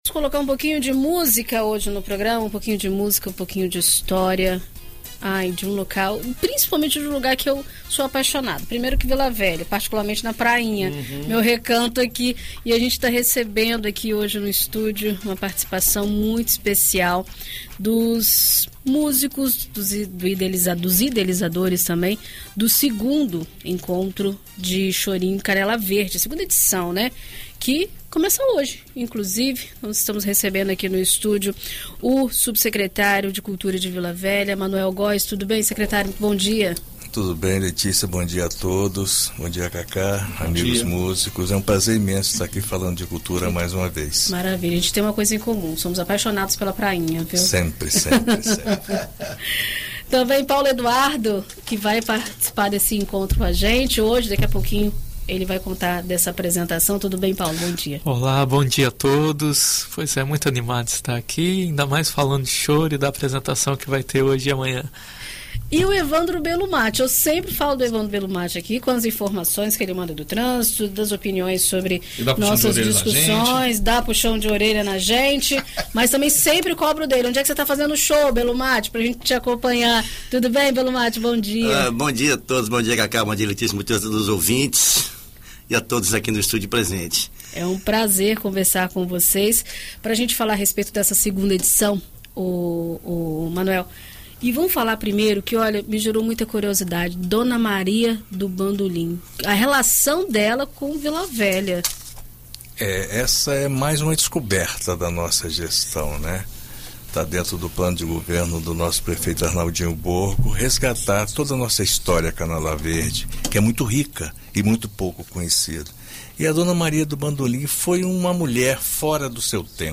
Em entrevista à BandNews FM Espírito Santo nesta terça-feira (06)
conversam sobre as atividades culturais na localidade e aproveitam para exibir um pouco da música que será apresentada.
ENT-CHORINHO-CANELA-VERDE-part-1.mp3